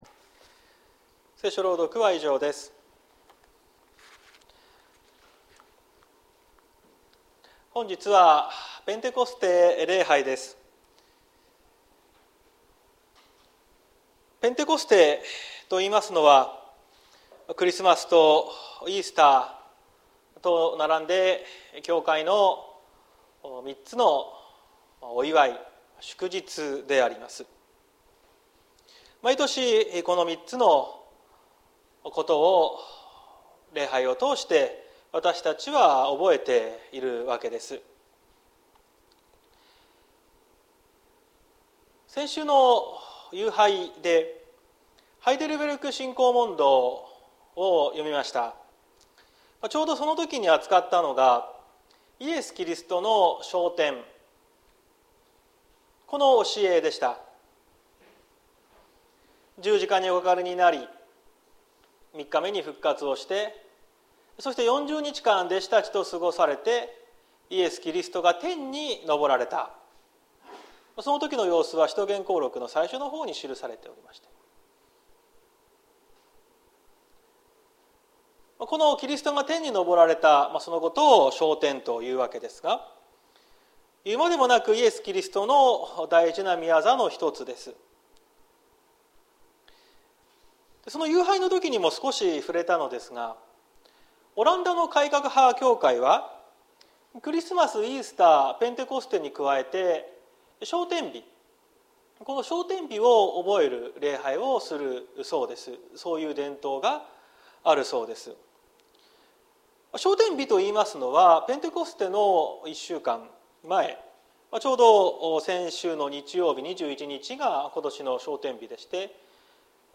2023年05月28日朝の礼拝「神の偉大な御業」綱島教会
説教アーカイブ。
本日はペンテコステ礼拝。